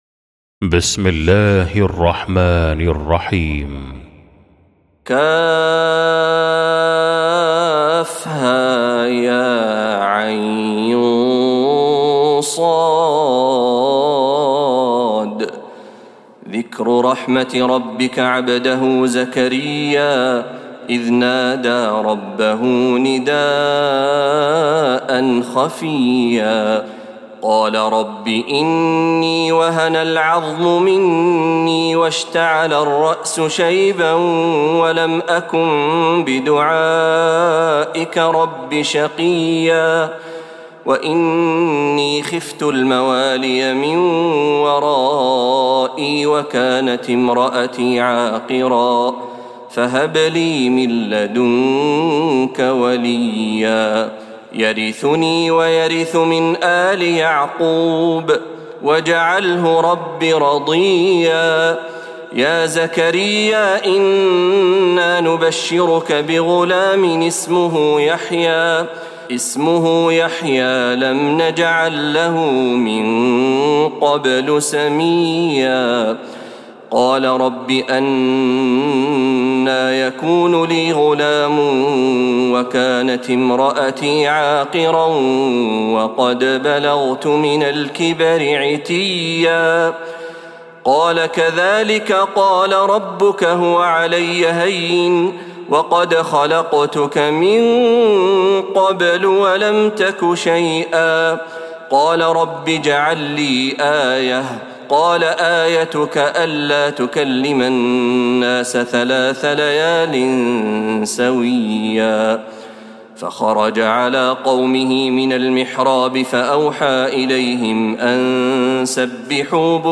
سورة مريم | Surah Maryam > مصحف تراويح الحرم النبوي عام 1446هـ > المصحف - تلاوات الحرمين